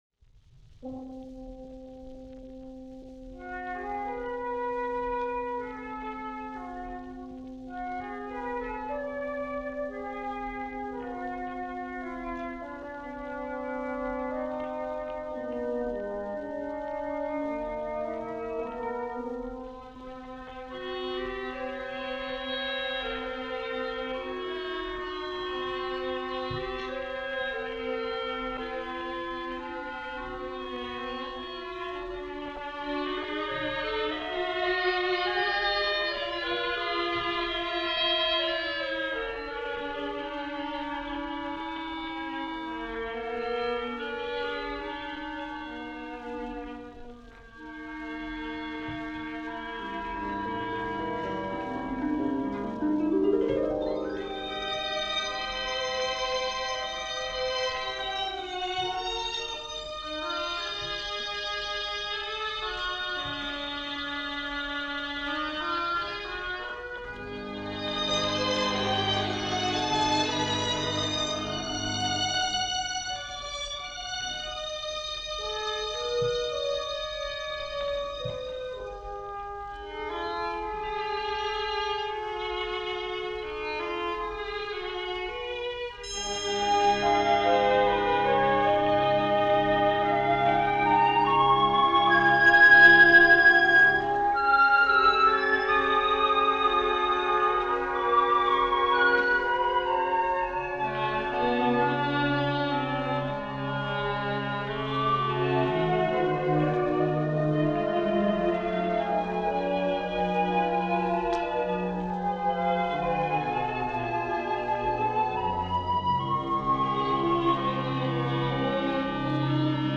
haunting
recorded during a studio concert circa 1951-1952.